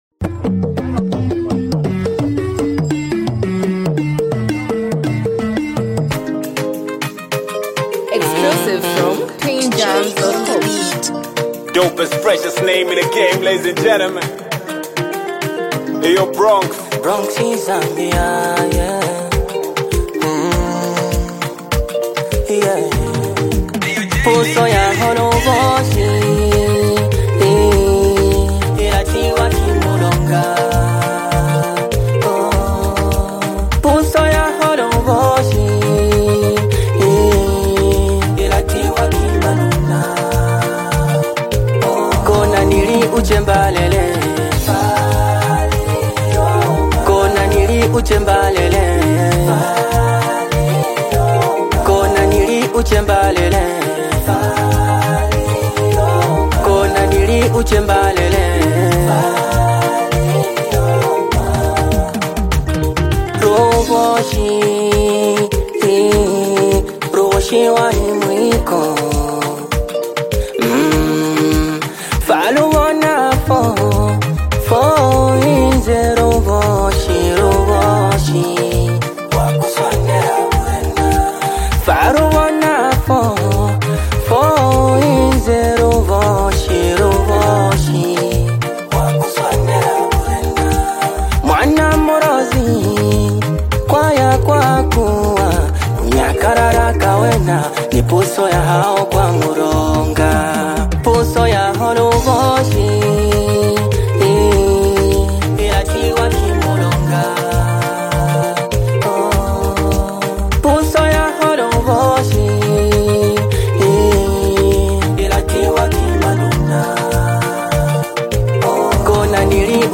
emotionally charged song
raw emotion
steady, melodic instrumental